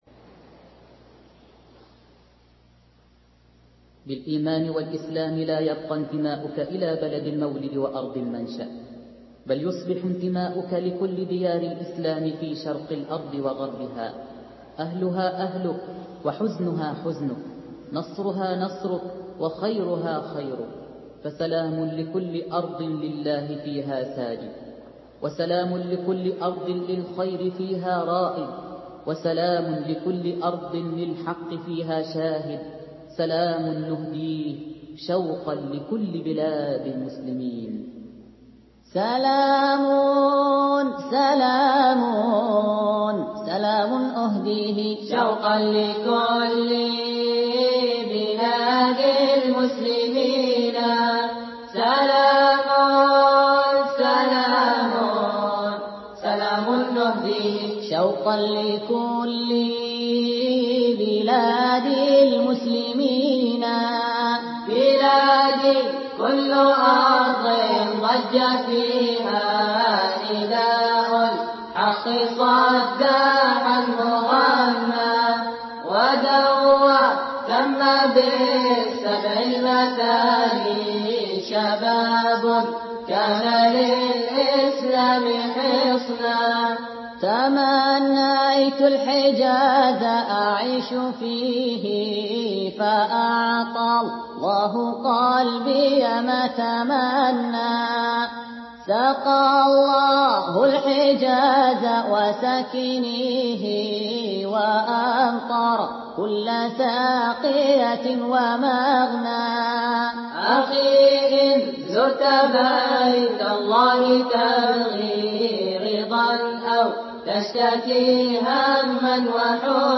أناشيد